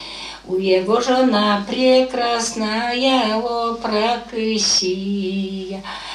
Произношение слова жена как /жона/ с лабиализованным предударным гласным
/у-йе-го” жо-на” пр’е-кра”-снаа-йаа о-праа-кы-с’и”-йа/